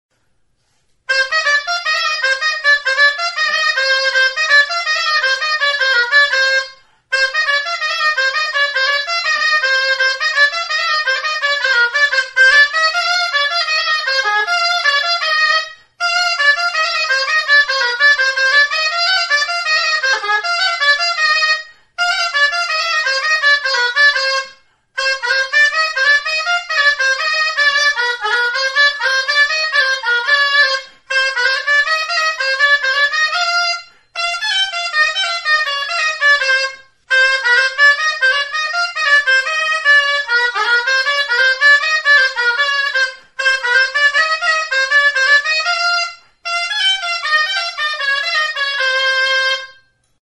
Aerophones -> Reeds -> Double (oboe)
Recorded with this music instrument.
Mihi bikoitzeko soinu-tresna da. Metalezko tutu konikoa du.